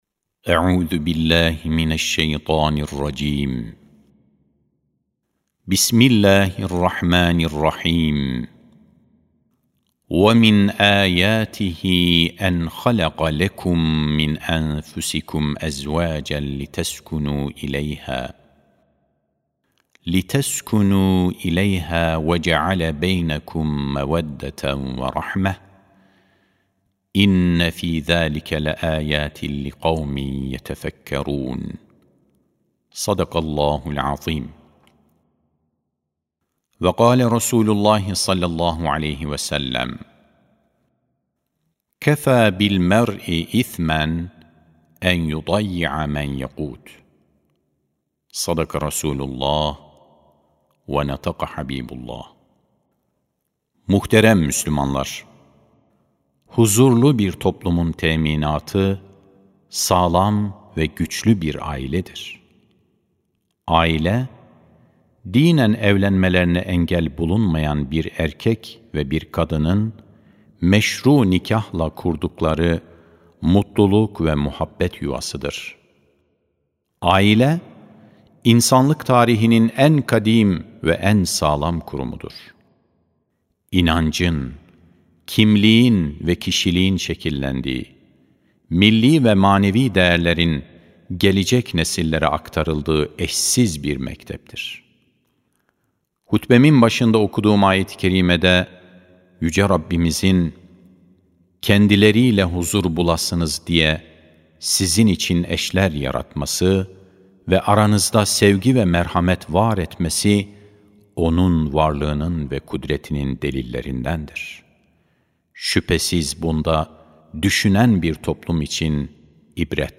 12.09.2025 Cuma Hutbesi: Peygamberimiz ve Aile Ahlakı (Sesli Hutbe, Türkçe, Arapça, İngilizce, Rusça, Fransızca, İtalyanca, İspanyolca, Almanca)
Sesli Hutbe (Peygamberimiz ve Aile Ahlakı).mp3